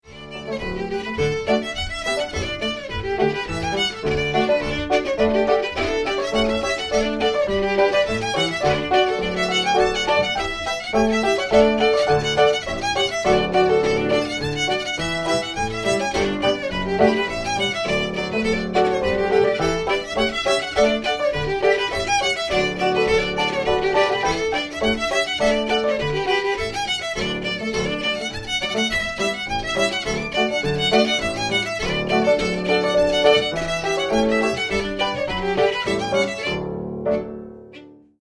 Alice Robertson - pipe reel by Robert Meldrum
Popular as a traditional reel within the Cape Breton fiddle tradition